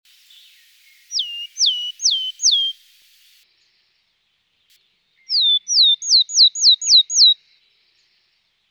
Die «Weidenmeise» äussert im Gesang eine Serie von eher langen, leicht absteigenden Tönen («ziüh ziüh ziüh ziüh», Sonogramm unten, Beispiel
Mesangeborealedessaulescopie.mp3